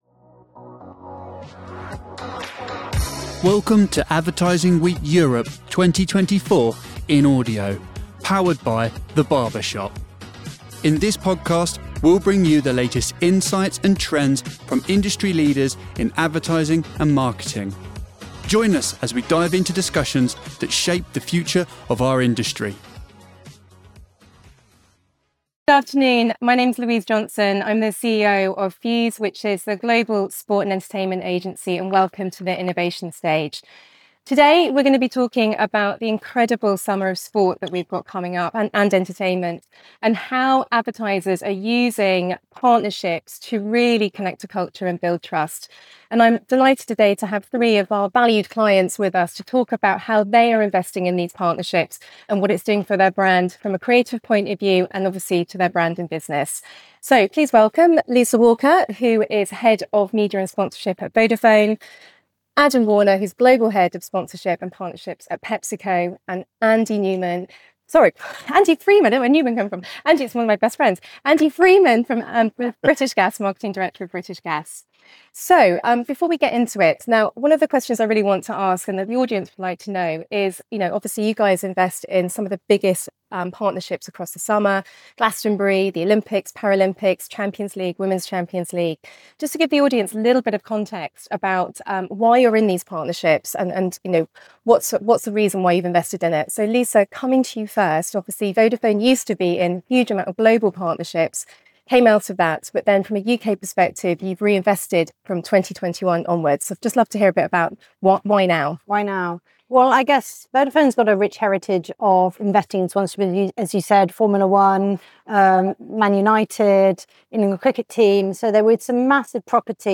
Explore the power of sports and entertainment partnerships in this dynamic session with leaders from Fuse, Vodafone, Pepsico, and British Gas.